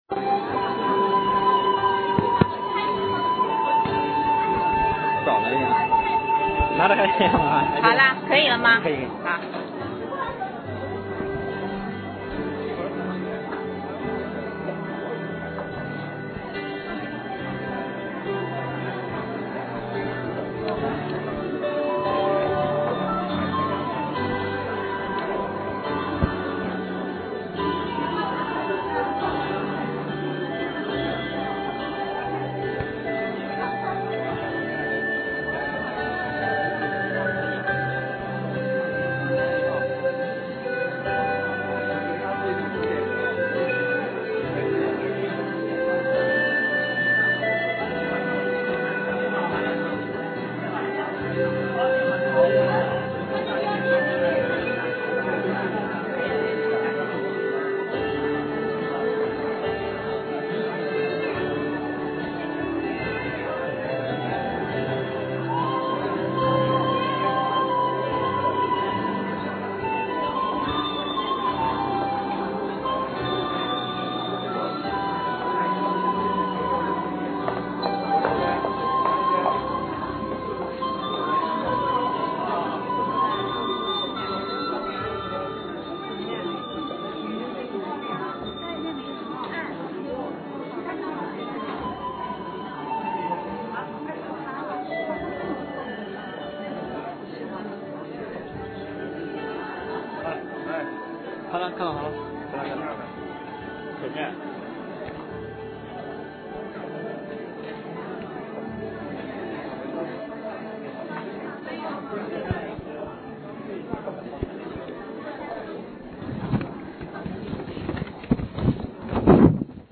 洗礼讲道：今天救恩到了这家（2007年6月10日，附音频 ）
编者按：这是一次洗礼前的讲道，这次洗礼，共有25位弟兄姐妹归入主的名下。